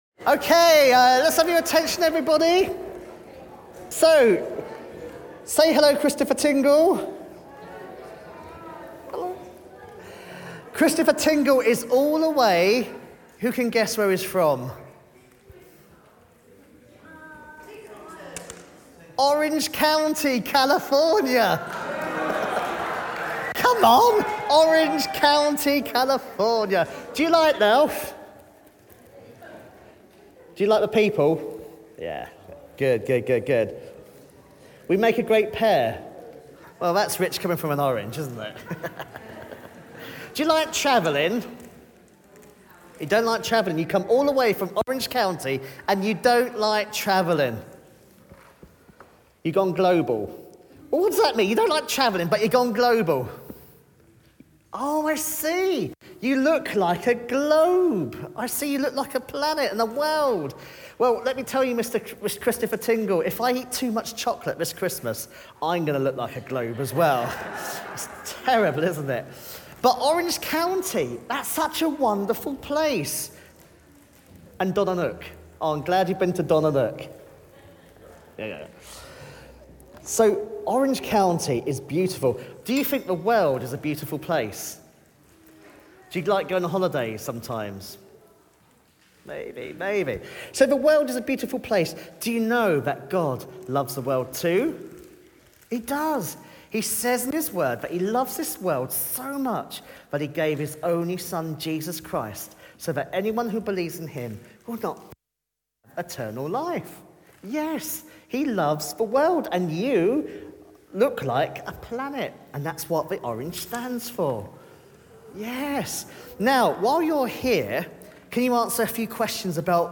Christingle service 2023